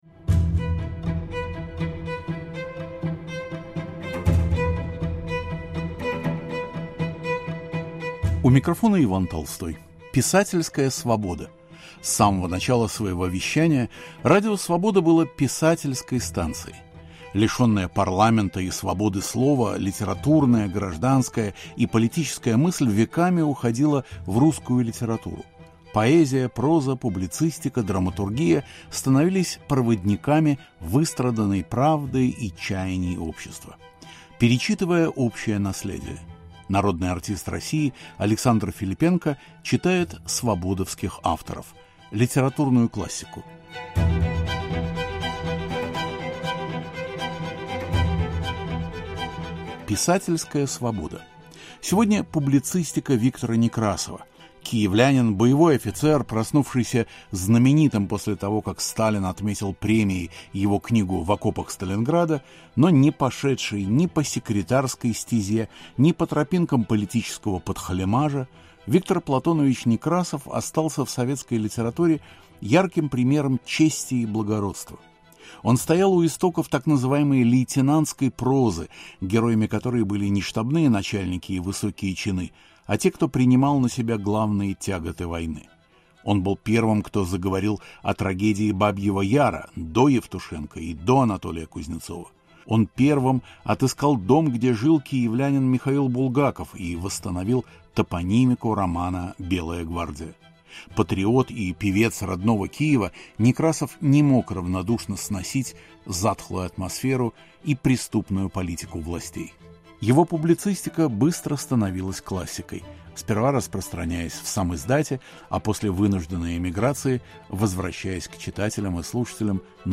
Александр Филиппенко читает подборку публицистики Виктора Некрасова - «Камень в Бабьем Яру» и «Кому это нужно?»